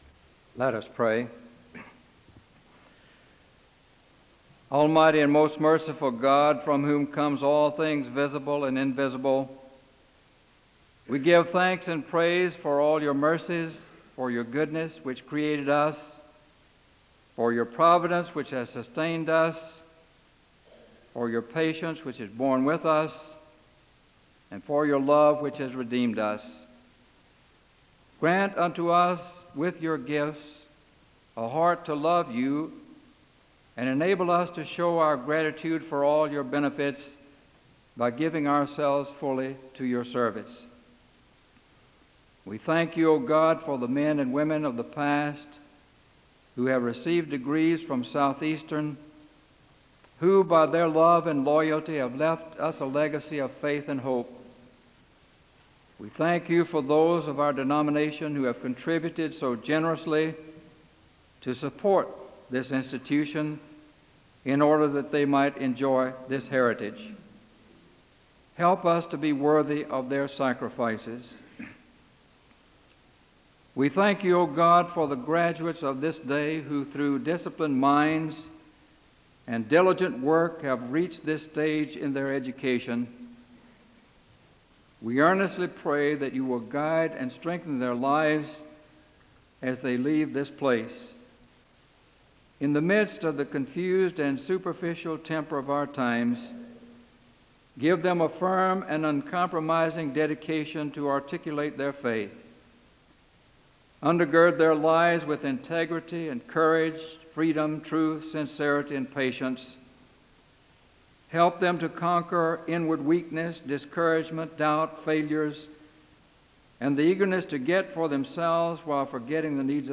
The service opens in prayer (0:00-3:10). Scripture is read aloud from Genesis 4:1-12 (3:10-6:10).
Commencement ceremonies